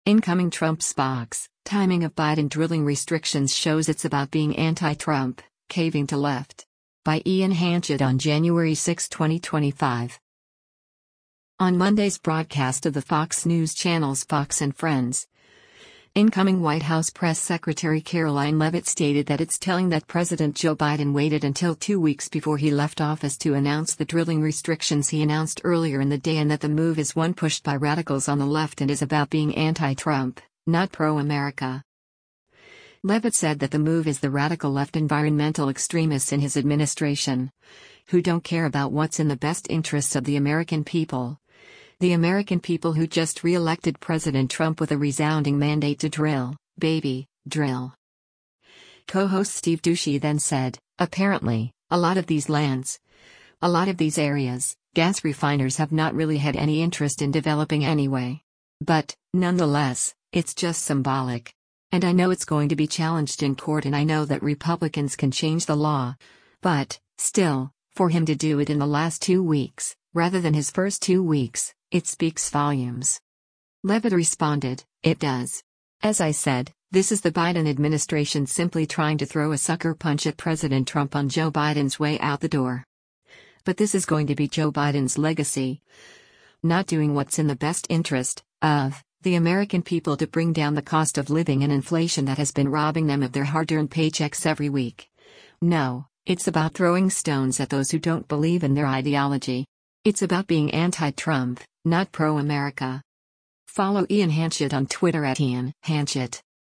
On Monday’s broadcast of the Fox News Channel’s “Fox & Friends,” incoming White House Press Secretary Karoline Leavitt stated that it’s telling that President Joe Biden waited until two weeks before he left office to announce the drilling restrictions he announced earlier in the day and that the move is one pushed by radicals on the left and is “about being anti-Trump, not pro-America.”